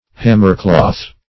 Hammercloth \Ham"mer*cloth`\ (-kl[o^]th; 115)
hammercloth.mp3